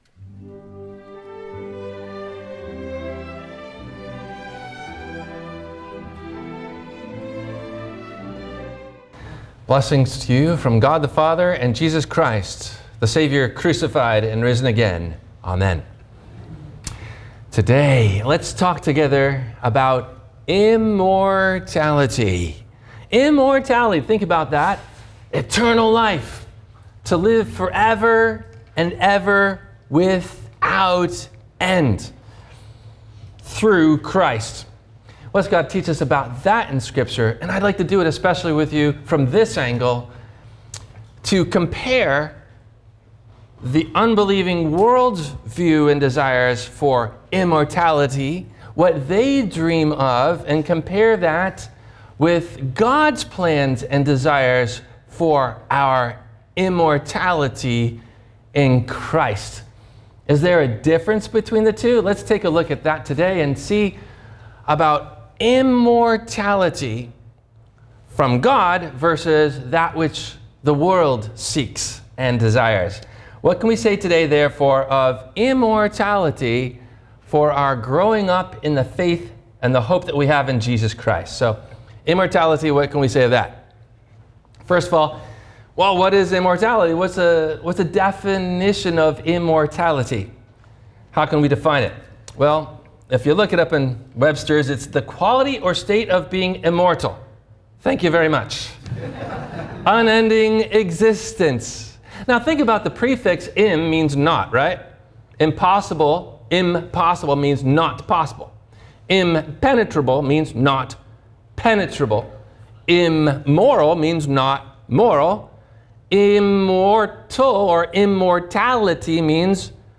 God’s Plan for Immortality versus the World’s – WMIE Radio Sermon – May 04 2026 - Christ Lutheran Cape Canaveral
Including Intro No closing Plug.